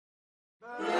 Gunshot Rifle
# gun # rifle # shot # bang About this sound Gunshot Rifle is a free sfx sound effect available for download in MP3 format.
547_gunshot_rifle.mp3